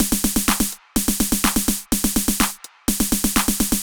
Beat 06 No Kick (125BPM).wav